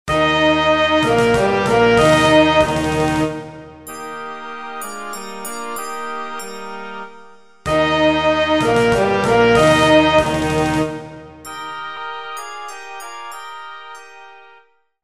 Kolędy